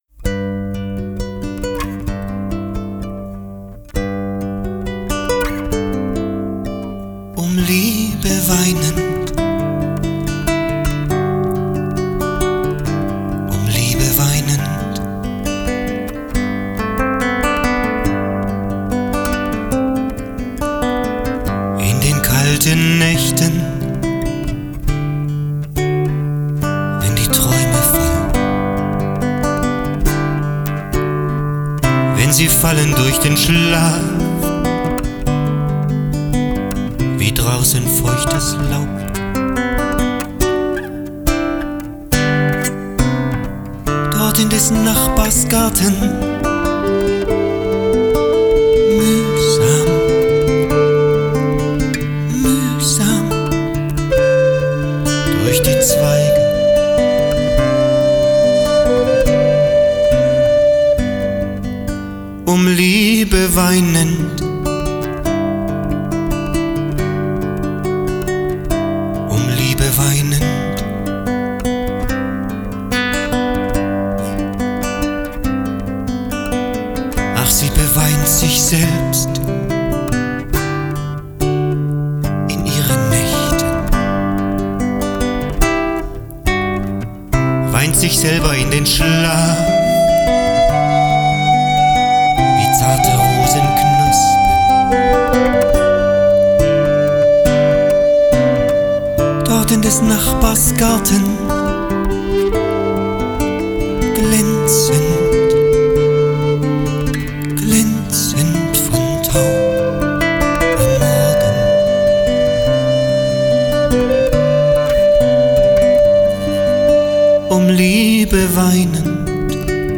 singt, er spielt, er schreibt, er hat die musik im kopf (nein; er würde, wäre er jetzt hier, widersprechen: im herzen). hier und da steht er auf der bühne, aber er lässt sich gerne finden. dann allerdings, gibt er alles: seinen chanson.